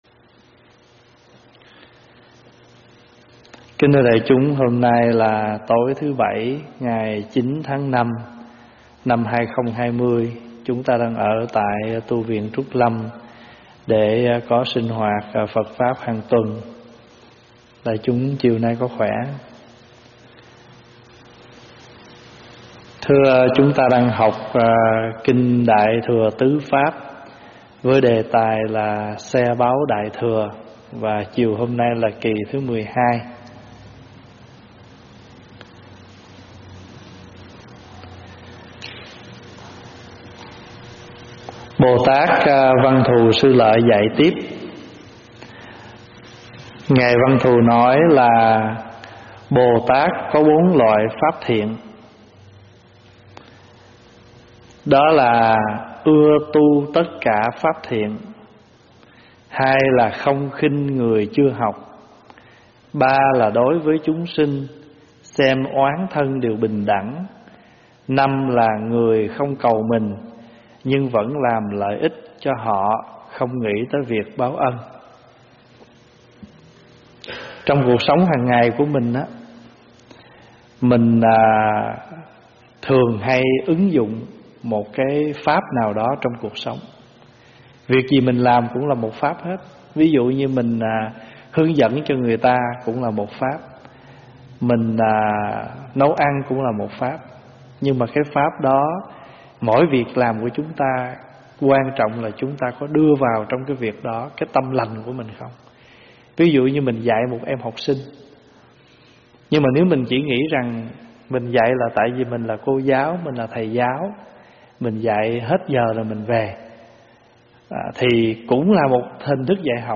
Thuyết pháp online
giảng tại tv Trúc Lâm